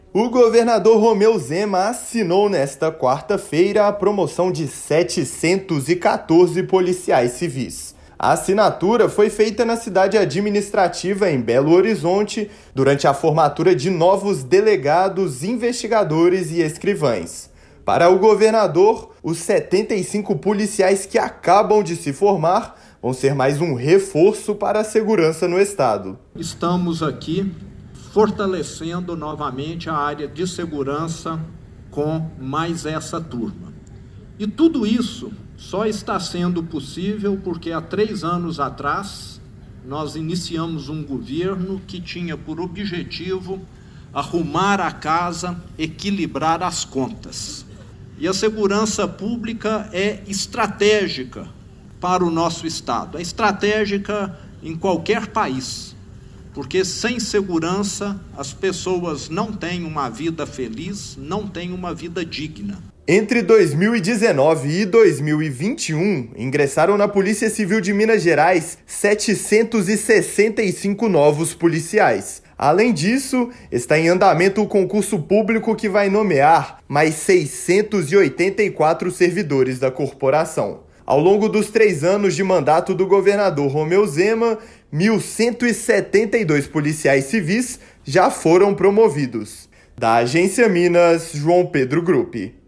[RÁDIO] Estado promove 714 policiais civis
A assinatura da promoção foi feita pelo governador Romeu Zema, durante a formatura de servidores da Polícia Civil. Ouça a matéria de rádio.